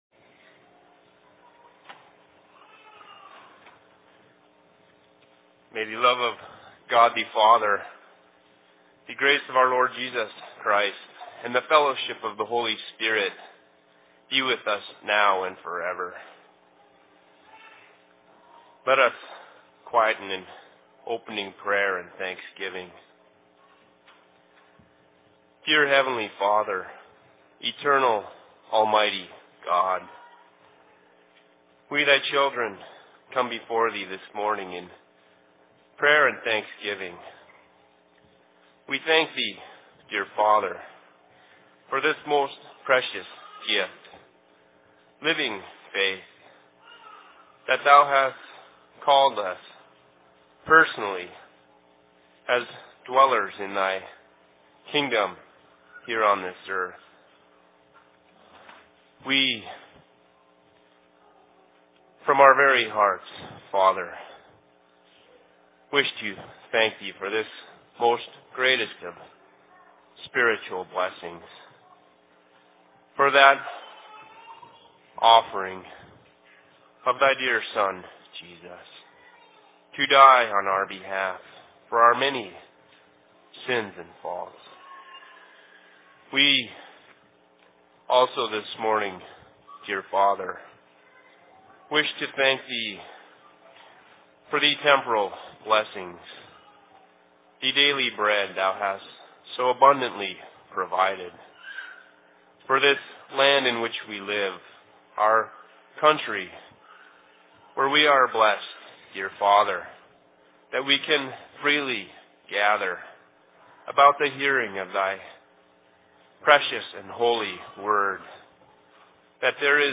Sermon in Seattle 29.01.2006